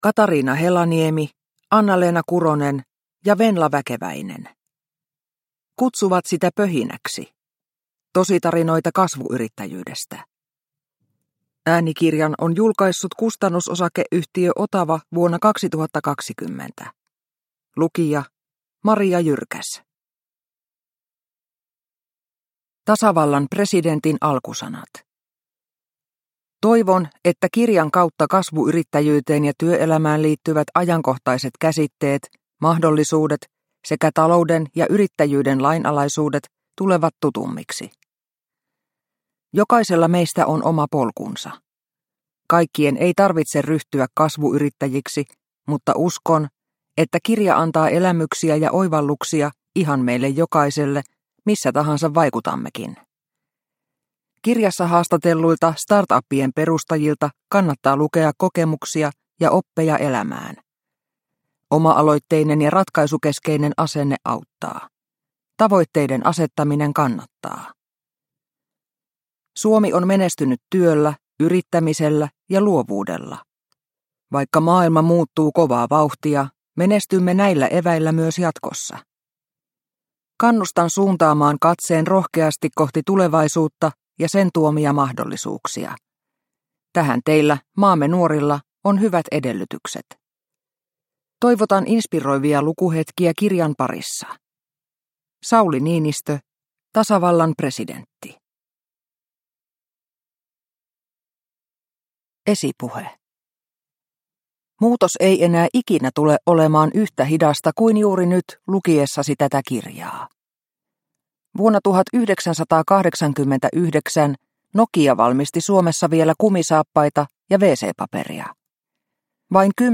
Kutsuvat sitä pöhinäksi – Ljudbok – Laddas ner